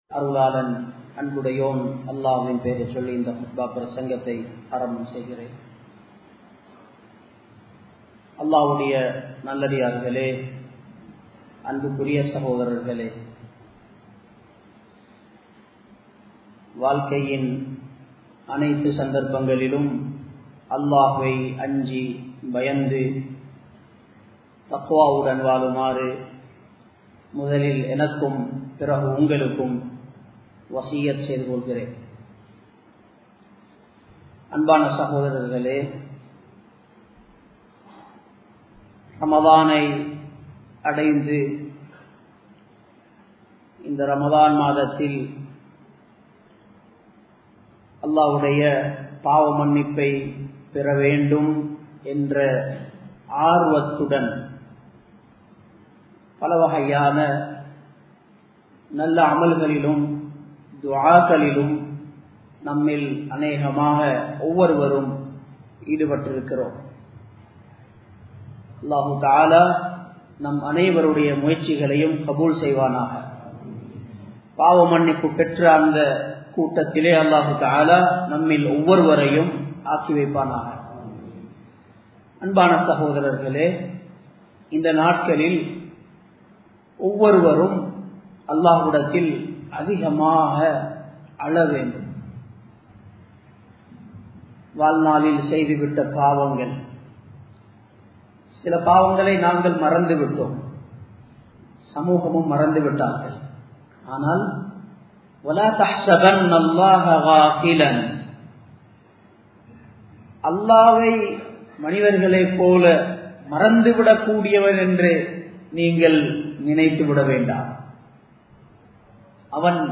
Allahvukku Maattram Seiyatheerhal (அல்லாஹ்வுக்கு மாற்றம் செய்யாதீர்கள்) | Audio Bayans | All Ceylon Muslim Youth Community | Addalaichenai
Colombo 11, Samman Kottu Jumua Masjith (Red Masjith)